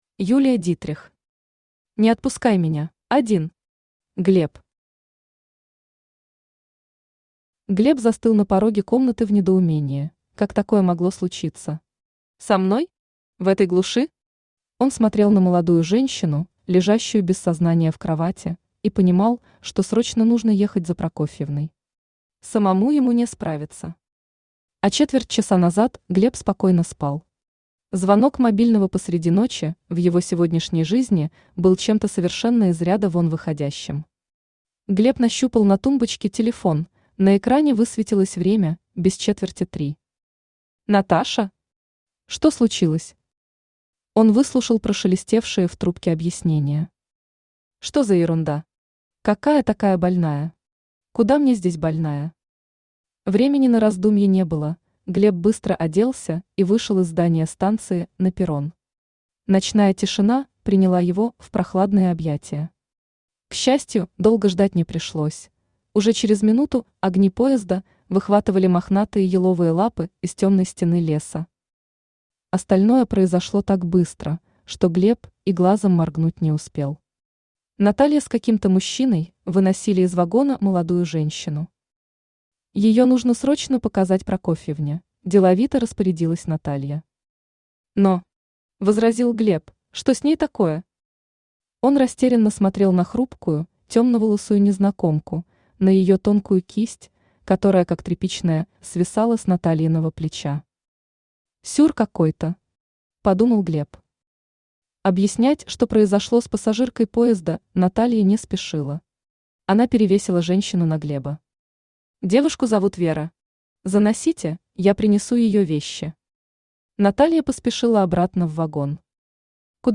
Аудиокнига Не отпускай меня | Библиотека аудиокниг